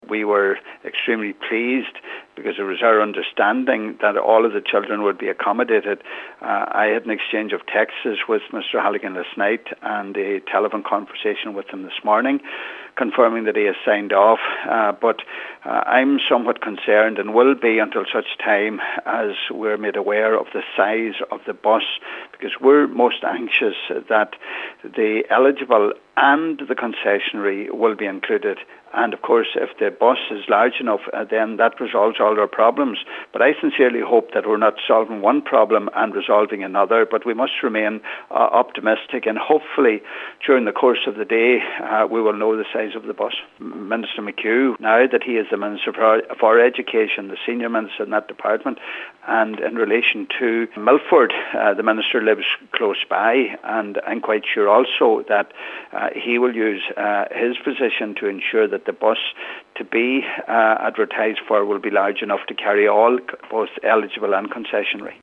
Deputy Pat the Cope Gallagher remains hopeful that those on concessionary tickets will be included:
Echoing that optimism, Donegal Deputy Charlie McConalogue ongoing representations are being made in a bid to resolve a similar issue in South Inishowen: